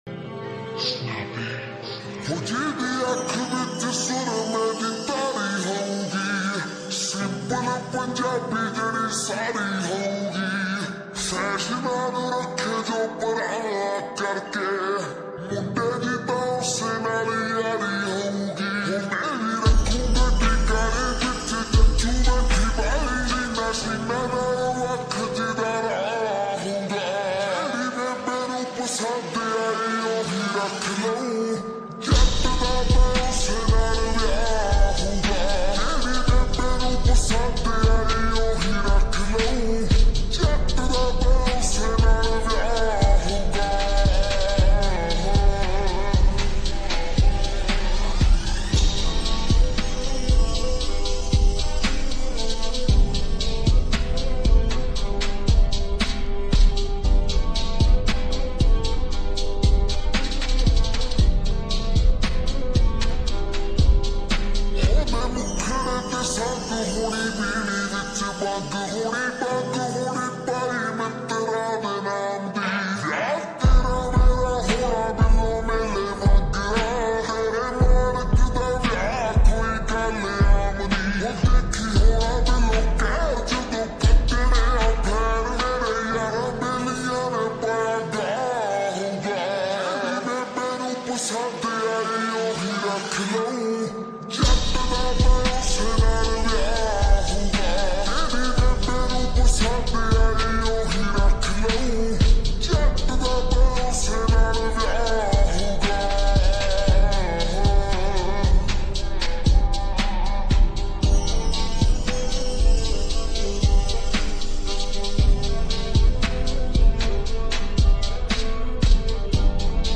SLOWED AND REVERB FULL SONG PUNJABI ULTRA HD SLOWED SONG